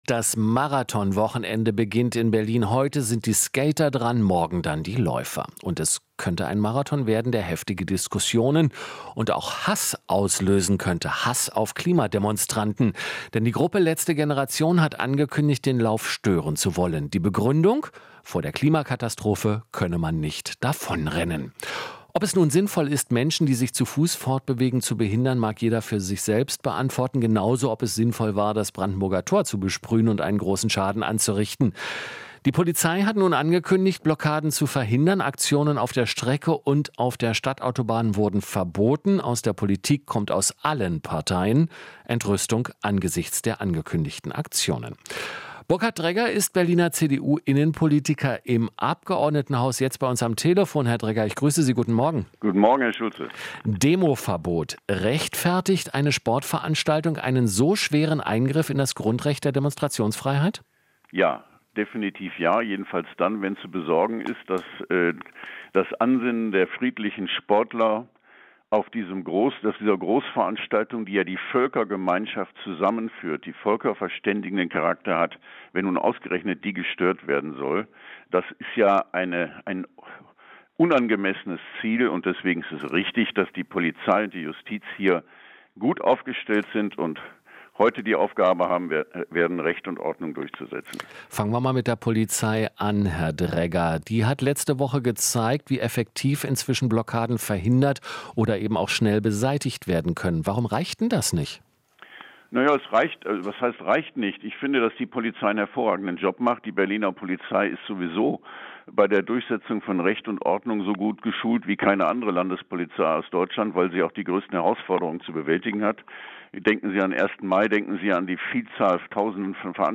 Interview - Dregger (CDU) verteidigt Demo-Verbot bei Berlin-Marathon